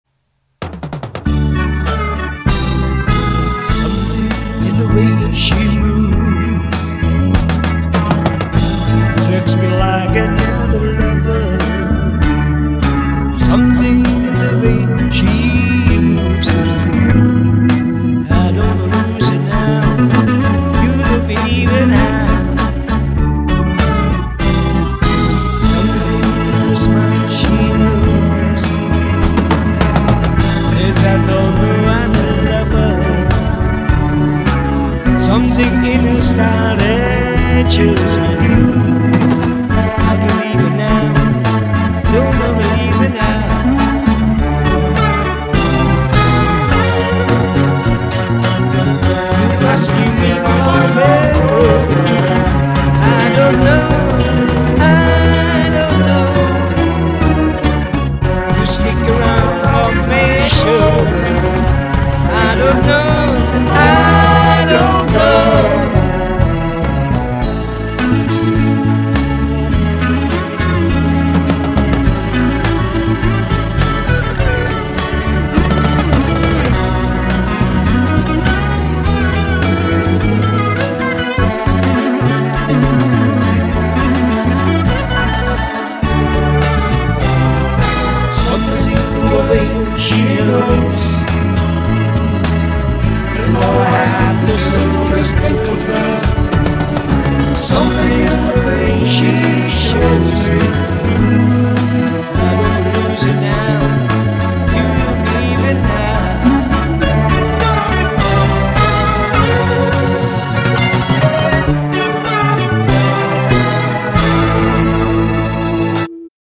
Schmusesongs